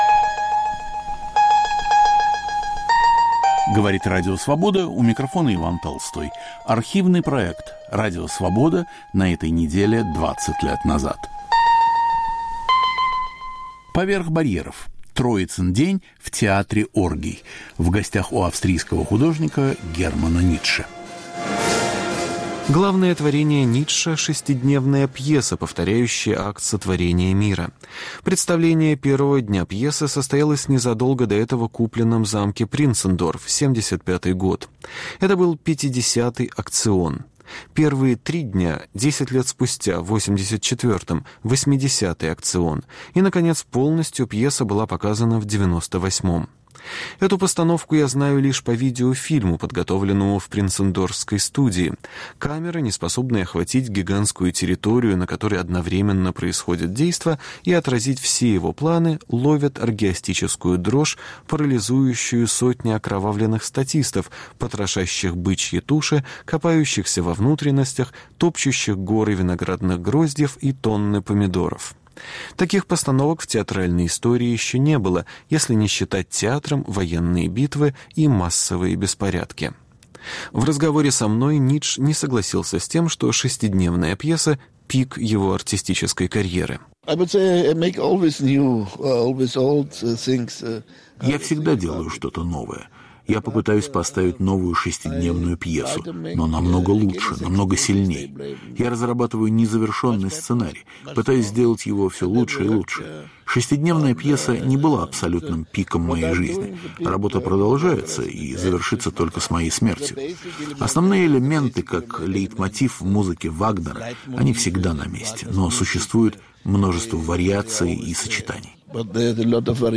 В передаче участвуют искусствовед и культуролог.